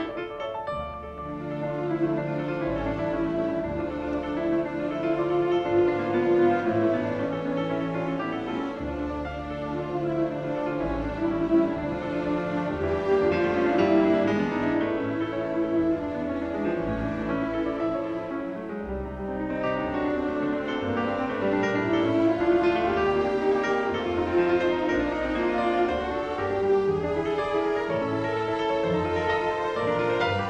0 => "Musique classique"